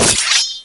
Sword_collide.wav